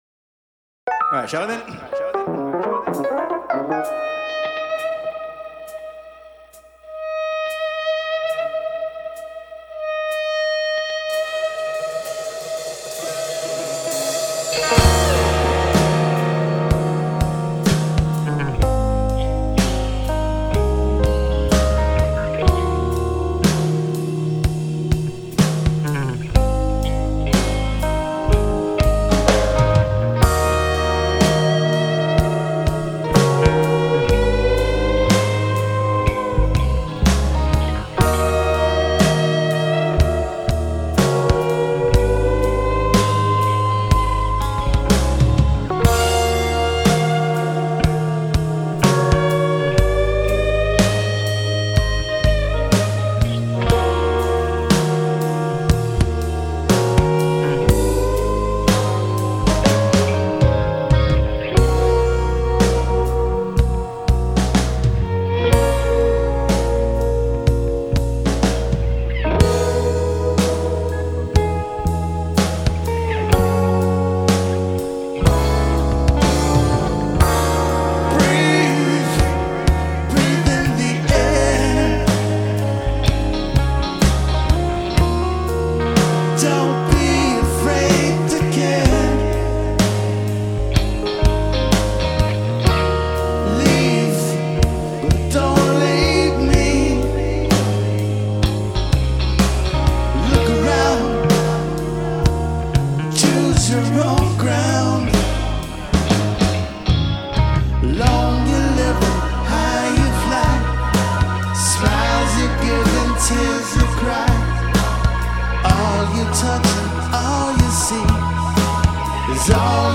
bass, vocals
drums, vocals Musician